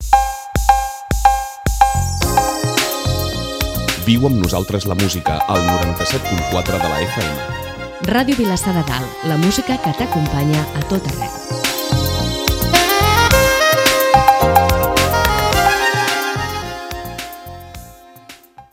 Indicatiu de nit de l'emissora